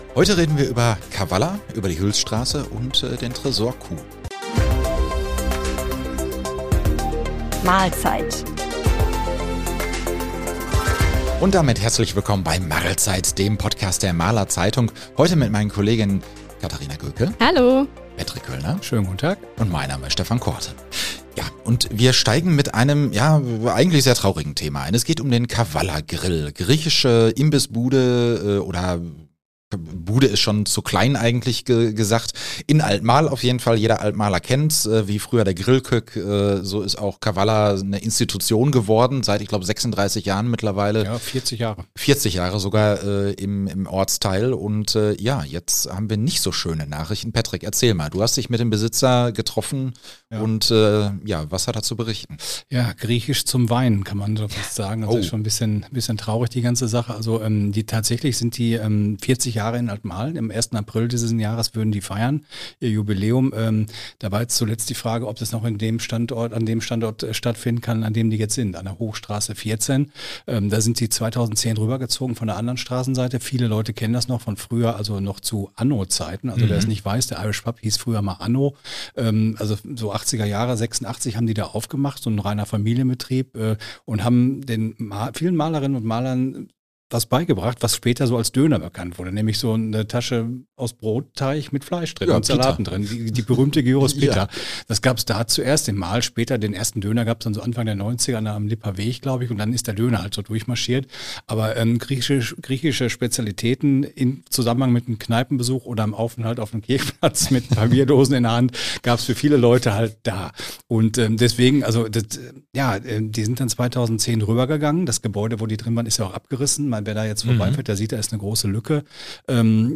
Einer Institution in Alt-Marl droht das Aus: Der Kawalla Grill ist in Gefahr. Außerdem: Endlich beginnt der Abriss der Arkaden in Hüls. Und wir sprechen darüber, wie sicher die Marler Sparkasse ist. Im Studio